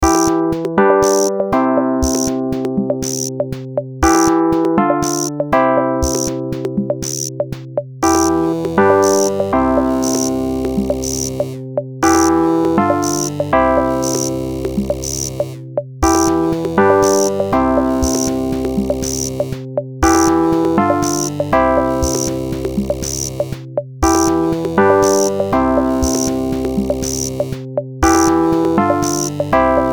Nice, like one of those electronic console organs in grampa's house.
Instrumental